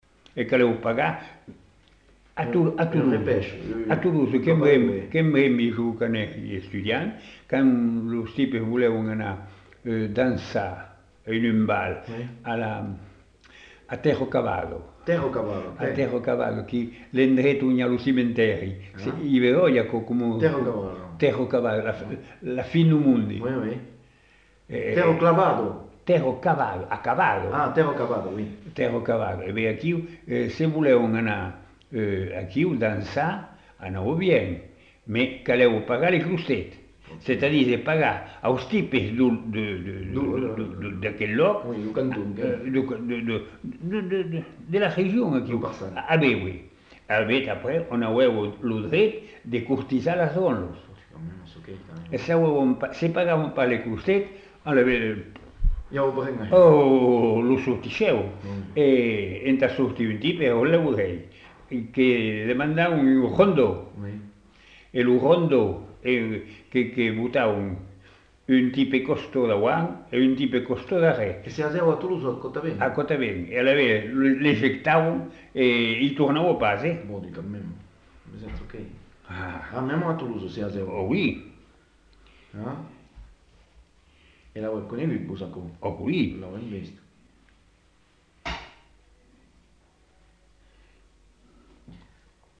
Lieu : Masseube
Genre : témoignage thématique